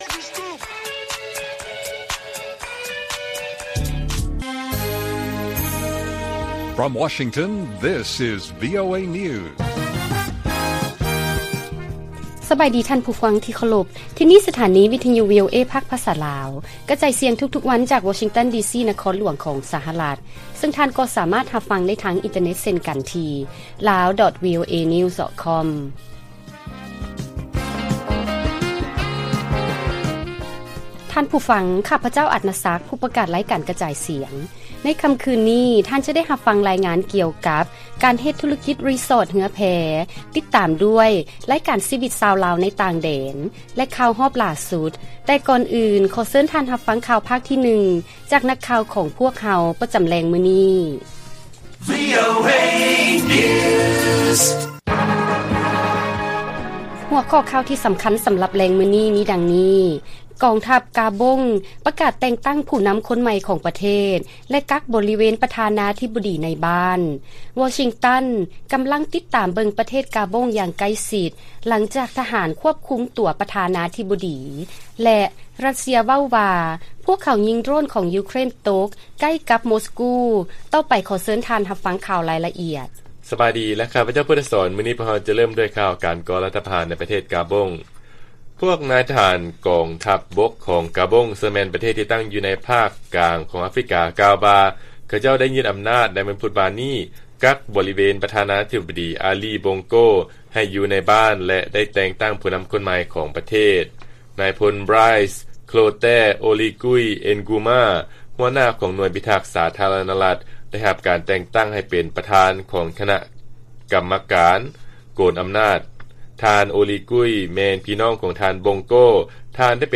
ລາຍການກະຈາຍສຽງຂອງວີໂອເອ ລາວ: ກອງທັບ ກາບົງ ປະກາດແຕ່ງຕັ້ງຜູ້ນຳຄົນໃໝ່ຂອງປະເທດ ແລະ ກັກບໍເວນປະທານາທິບໍດີ ໃນບ້ານ